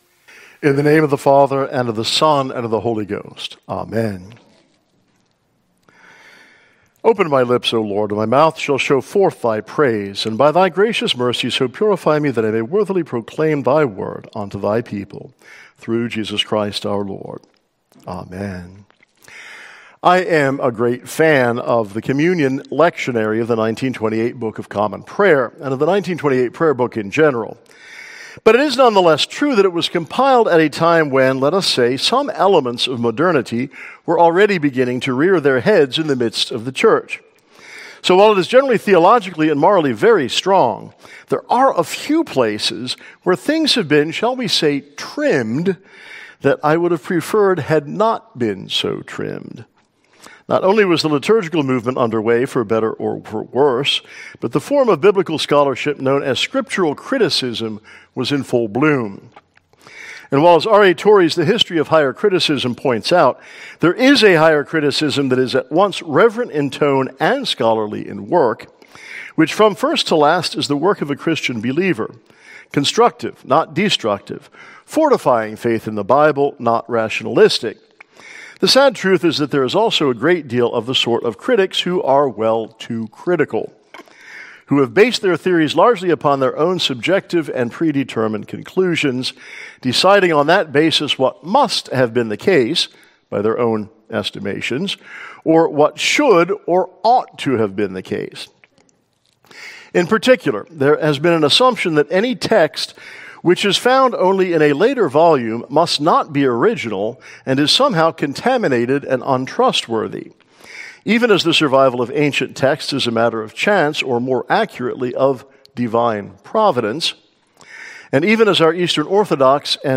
Listen to the sermon for the First Sunday after Easter.